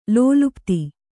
♪ lōlupti